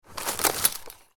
Taking Christmas Ornament Out Of Box Sound Effect
Hear the paper rustle as you take a Christmas ornament from its box.
Genres: Sound Effects
Taking-christmas-ornament-out-of-box-sound-effect.mp3